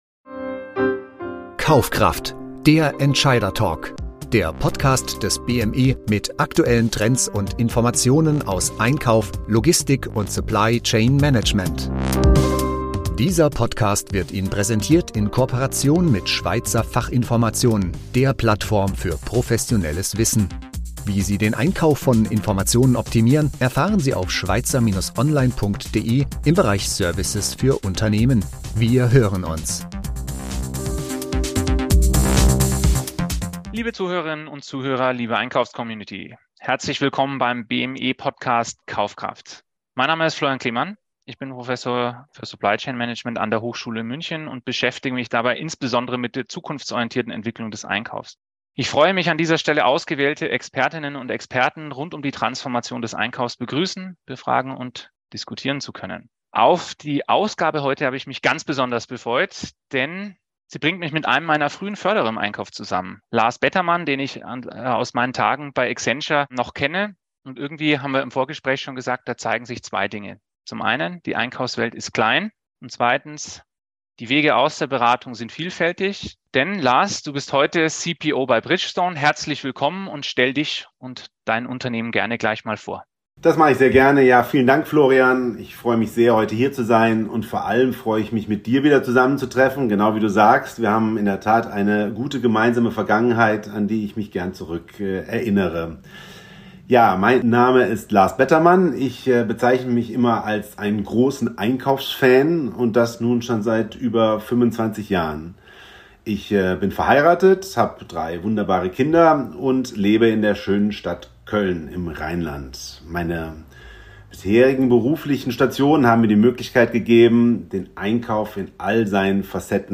Das Interview gibt wertvolle Einblicke in die Welt des Einkaufs und wie Unternehmen durch gelebte Resilienz gestärkt werden können.